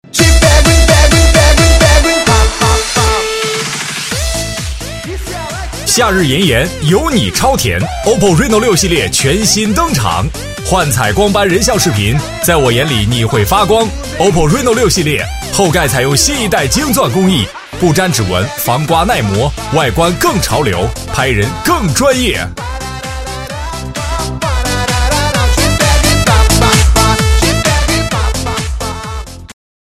男3号配音师
声音大气厚重，亦可激情磁性，声音非常适合专题类，宣传类稿件。
代表作品 Nice voices 促销 飞碟说 舌尖 专题片 促销-男3-激情活力+夏日炎炎，有你超甜.MP3 复制链接 下载 促销-男3-激情大气+ 途虎养车3周年庆.mp3 复制链接 下载